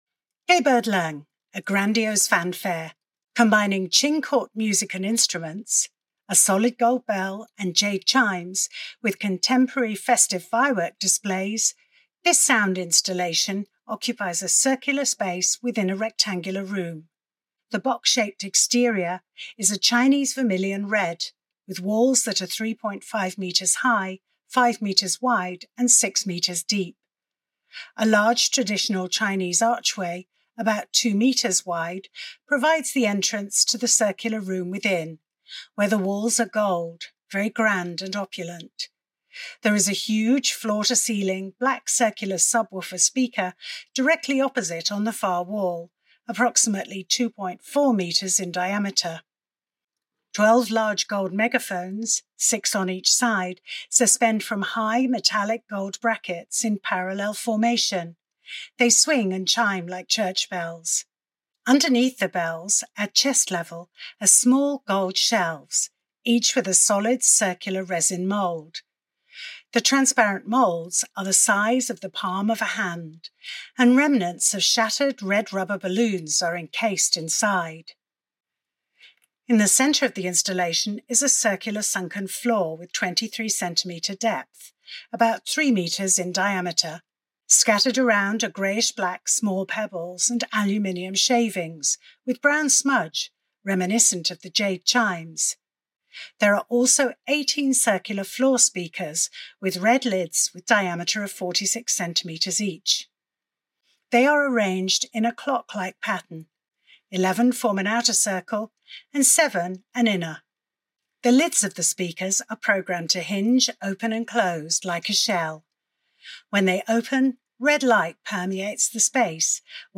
[Audio Description] No Boundaries: Reinterpreting Palace Museum Culture